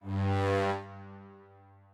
strings7_23.ogg